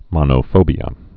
(mŏnō-fōbē-ə)